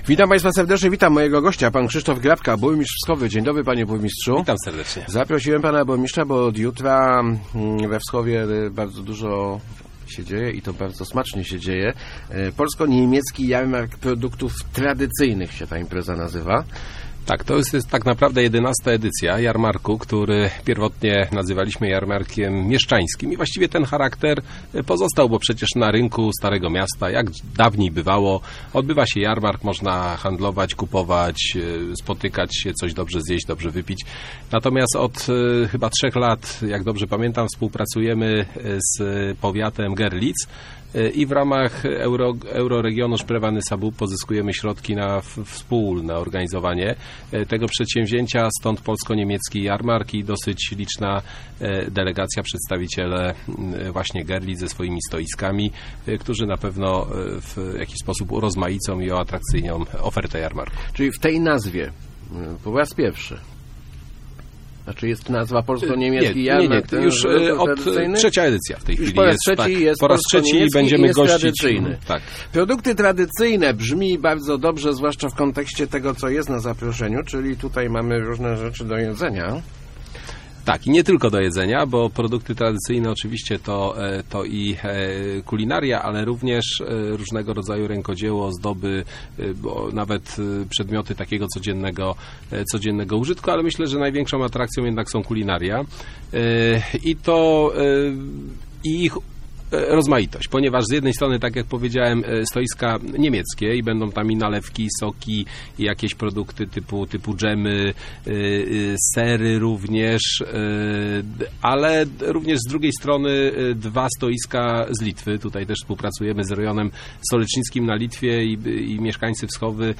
-Poza smakołykami będzie też sporo rękodzieła - mówił  w Rozmowach Elki burmistrz Wschowy Krzysztof Grabka.